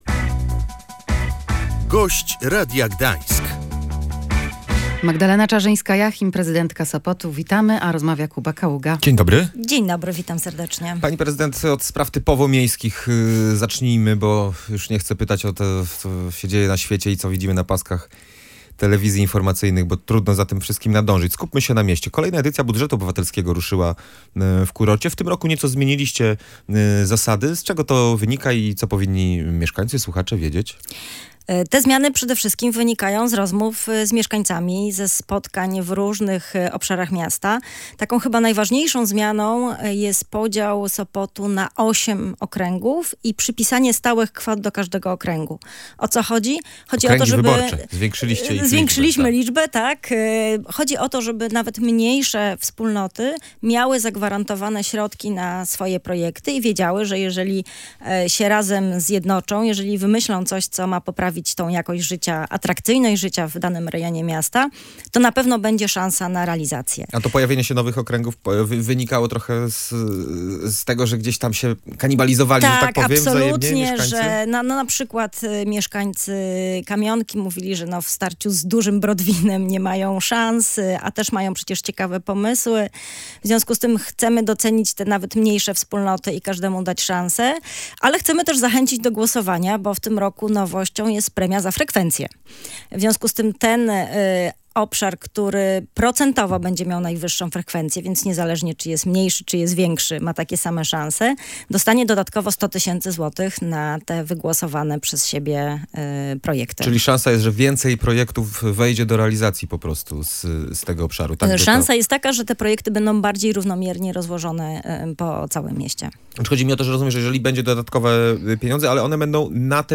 Prezydent Sopotu Magdalena Czarzyńska-Jachim mówiła w Radiu Gdańsk, że to element kampanii wyborczej i sprzeciwia się argumentom radnych.